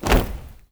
AFROFEET 5-L.wav